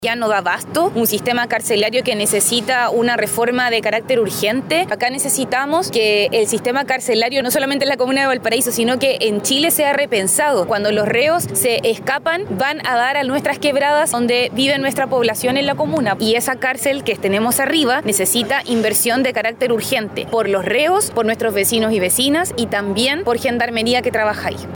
La alcaldesa de Valparaíso, Camila Nieto, realizó un duro análisis respecto del actual sistema carcelario.
cu-crisis-carcel-valpo-alcaldesa.mp3